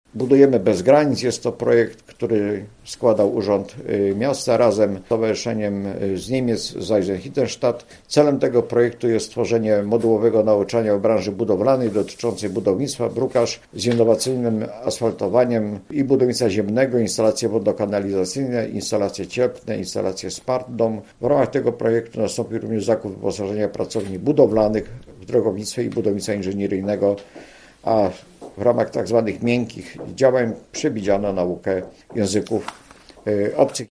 Udział w projekcie weźmie 175 uczestników. Mówi Tadeusz Jędrzejczak, wicemarszałek województwa lubuskiego: